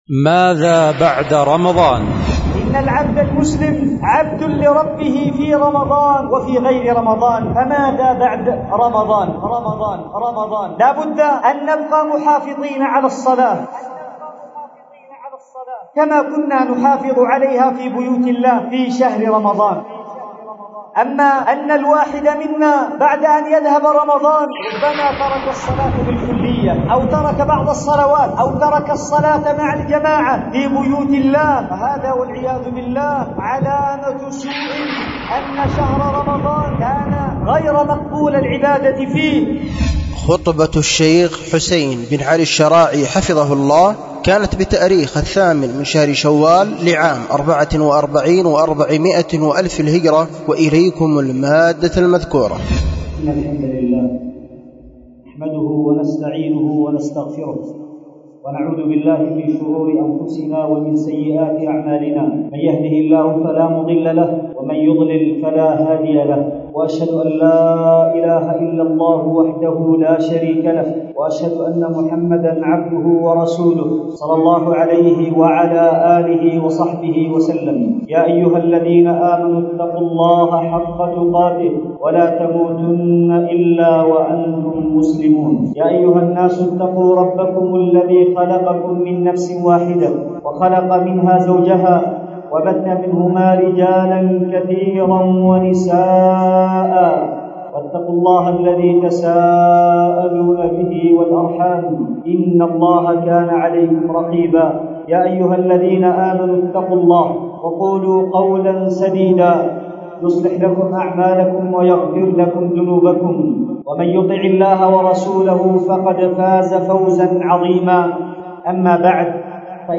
ماذا بعد رمضان ؟ خطبة